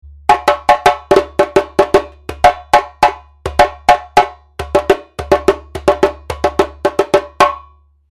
故に高音のキレに特徴が出ますが
またエッジも工夫して中音域の深みが出るようになってますので
全体のバランスが秀逸なジェンベです。
コロっとしていて、それでもキレがある、大勢で叩く際もガッツリソロを通せるサウンドです。